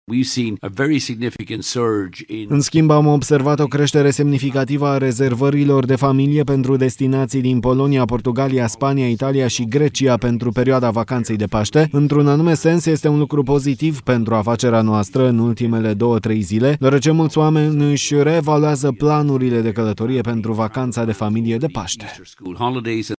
Cifra a fost avansată de CEO-ul companiei – Michael O’Leary – în cadrul unei conferințe de presă în Varșovia.
03mar-15-CEO-Ryanair-rezervari-vacantele-TRADUS.mp3